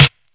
Snare (79).wav